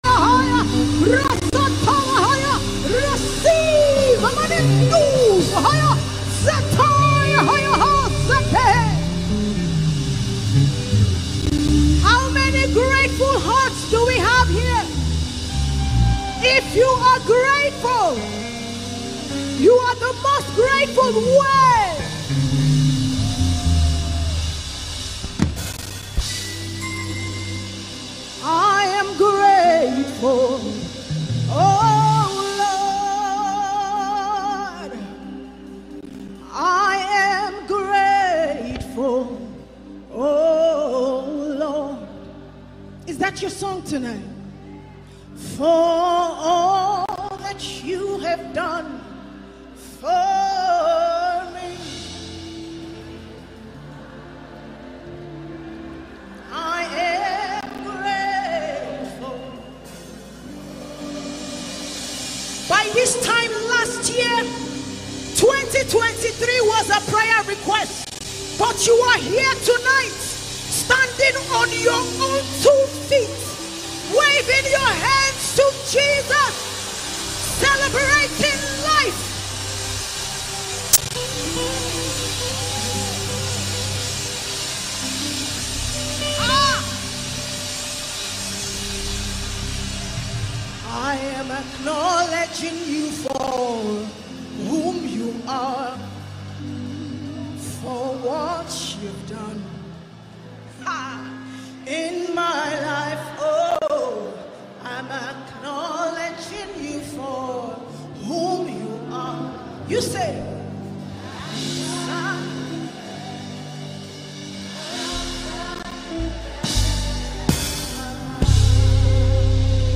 an annual mega-gospel concert